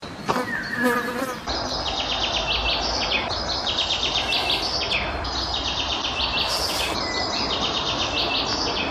Waldgeräusch 2: Hummel und Waldvögel / forest sound 2: bumblebee and forest birds